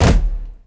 Waka KICK Edited (64).wav